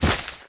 monster / wild_boar / drop1.wav
drop1.wav